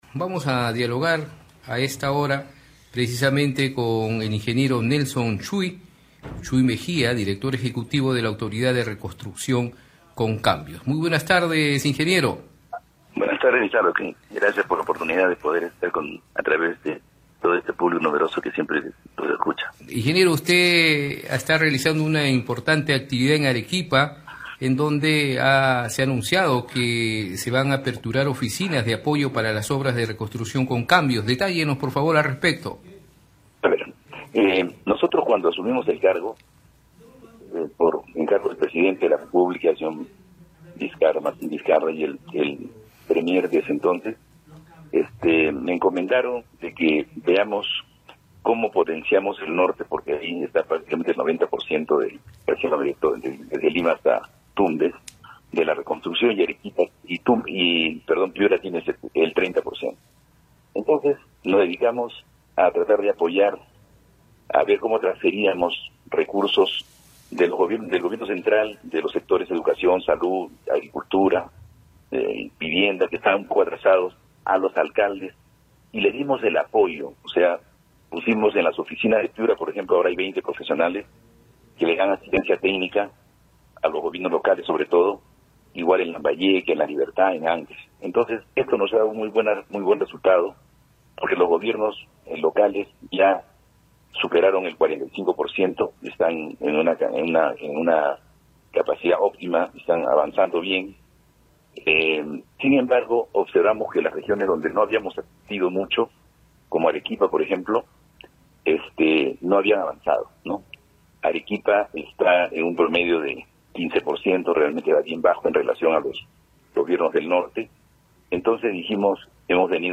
En declaraciones a Red de Comunicación Regional dijo que la meta permitirá más que duplicar el monto ejecutado el 2018 que llegó a los 1700 millones de soles.